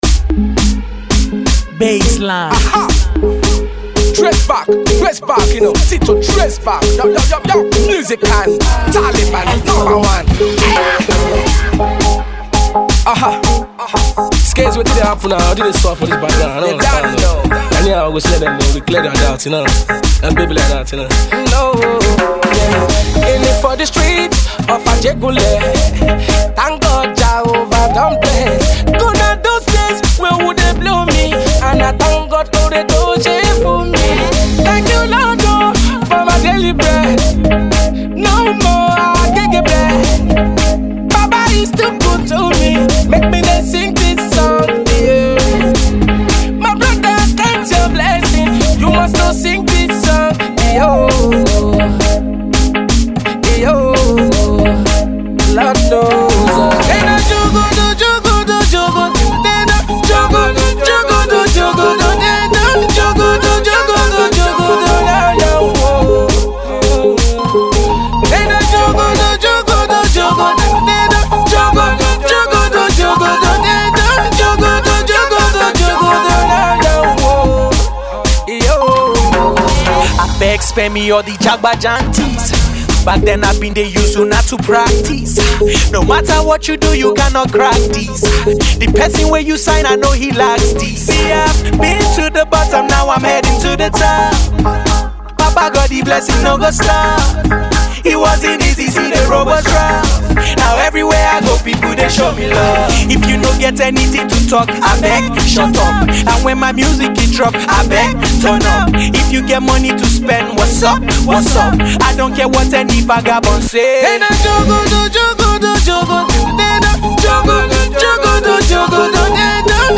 AudioRap
Dancehall single